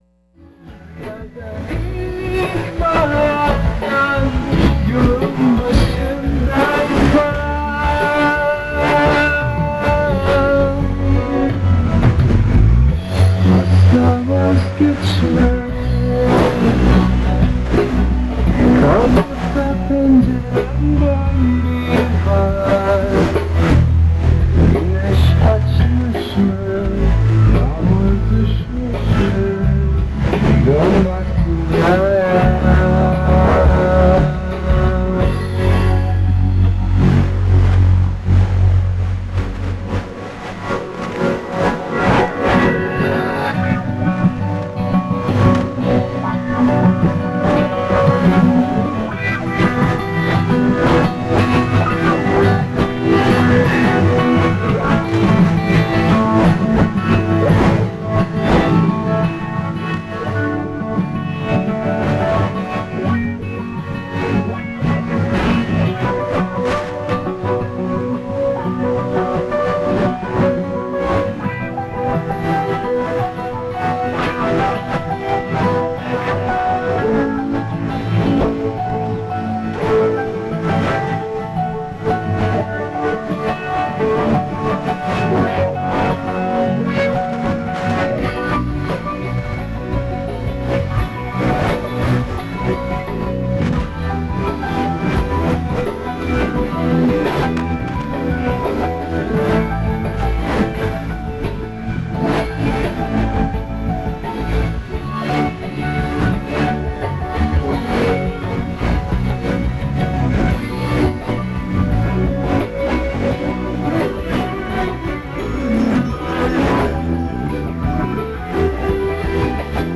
Decent signal into EM80. 49+40 with fading.
0251 UTC - music
0253 UTC - OM speaking in Spanish, signing off
0255 UTC - station music